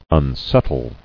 [un·set·tle]